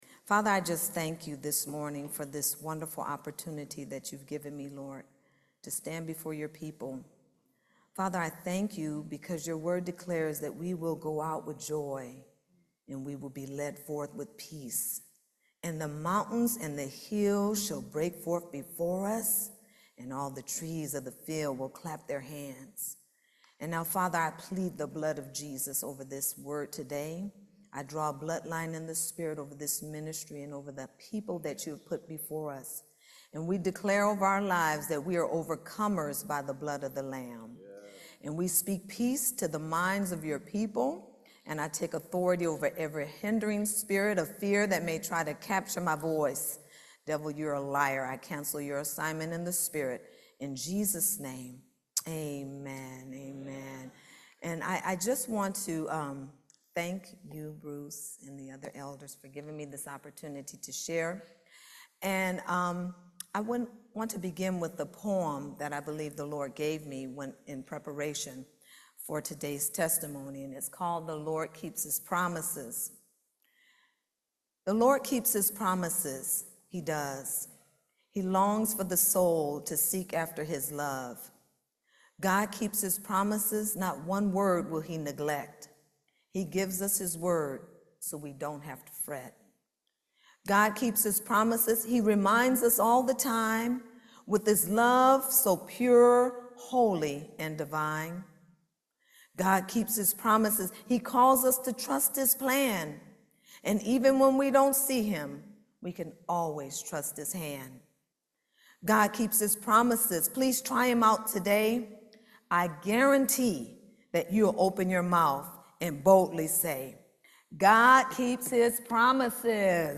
Series: Testimony
Service Type: Main Service